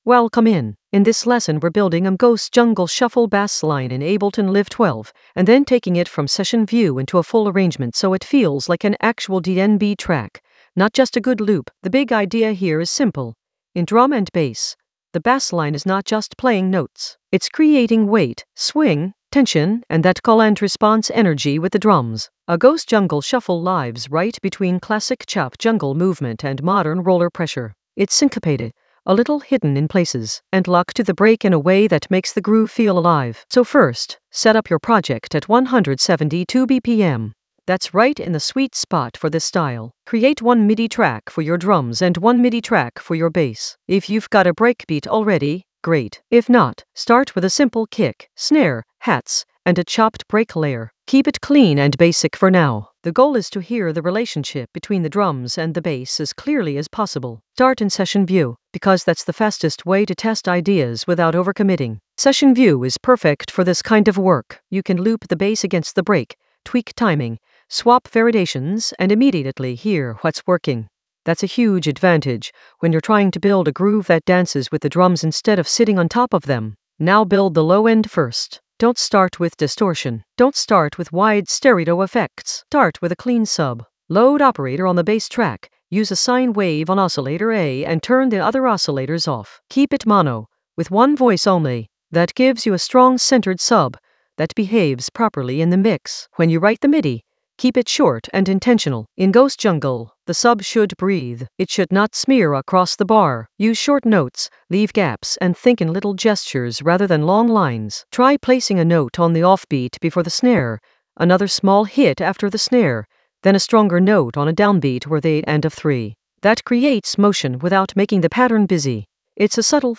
An AI-generated intermediate Ableton lesson focused on Ghost jungle shuffle using Session View to Arrangement View in Ableton Live 12 in the Basslines area of drum and bass production.
Narrated lesson audio
The voice track includes the tutorial plus extra teacher commentary.